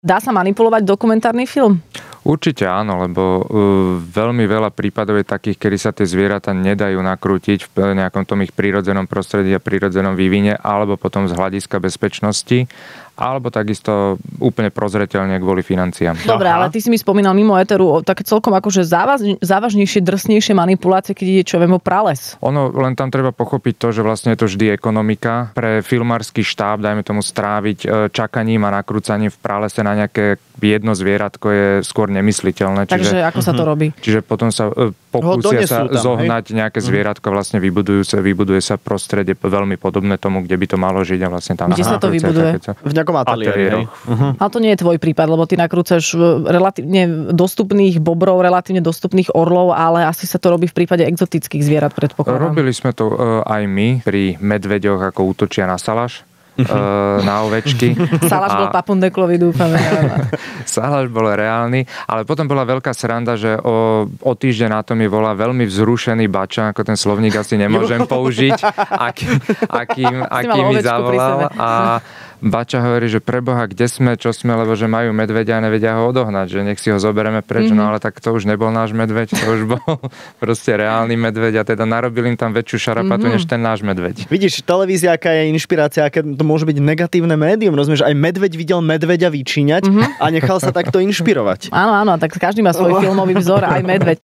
Dokumentarista v Rannej šou | Fun rádio